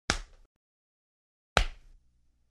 Звуки ударов руками, ногами
1. Удар раскрытой ладонью по лицу n2. Пощечина ладонью в лицо